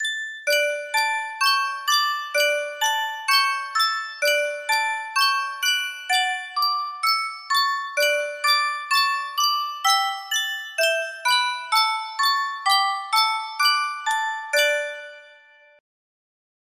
Superstar Music Box - Yankee Doodle R7 music box melody
Full range 60